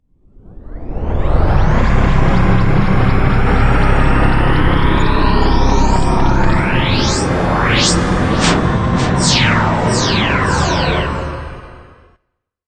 科幻小说的效果 12
Tag: 未来 托管架 无人驾驶飞机 金属制品 金属 过渡 变形 可怕 破坏 背景 游戏 黑暗 电影 上升 恐怖 开口 命中 噪声 转化 科幻 变压器 冲击 移动时 毛刺 woosh 抽象的 气氛